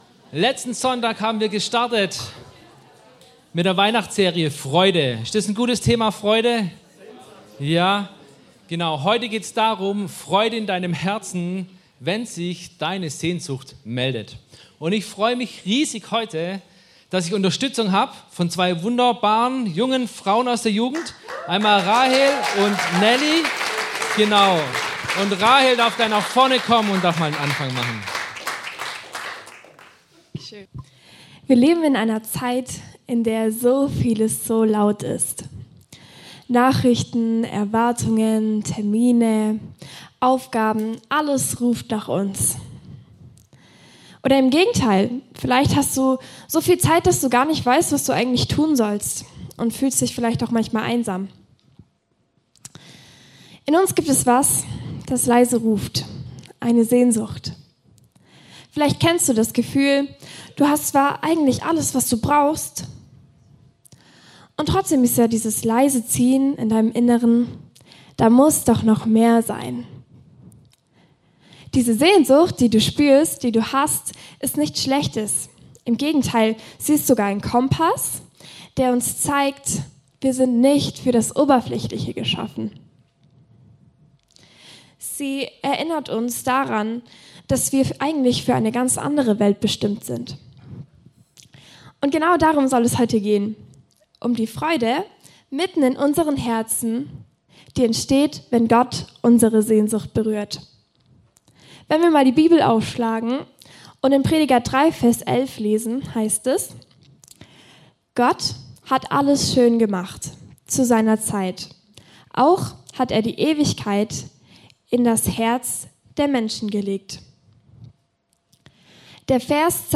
Die Predigt verbindet das mit der Weihnachtsgeschichte der Weisen aus dem Morgenland: Sie folgen dem Stern aus Sehnsucht nach dem König, machen Umwege, suchen erst am falschen Ort, bleiben aber auf dem Weg – bis sie Jesus finden und "große Freude" erleben (Matthäus 2).